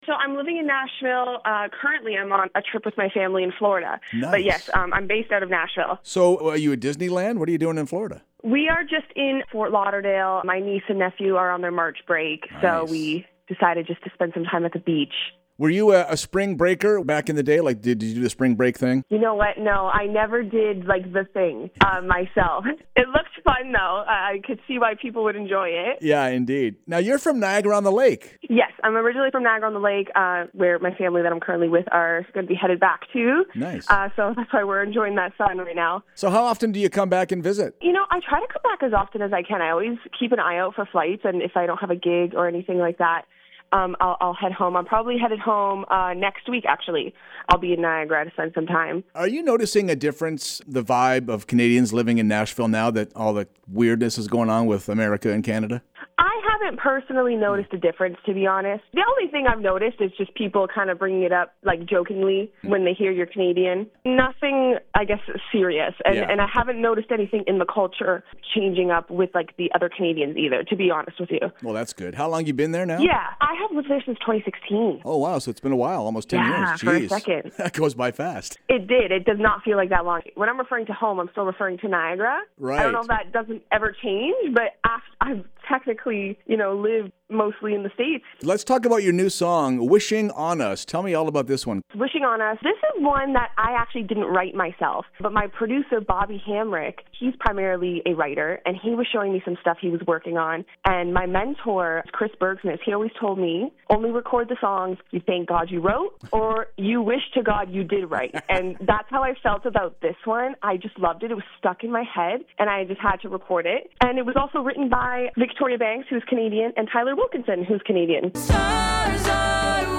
Tune in every Friday morning for weekly interviews, performances, everything LIVE!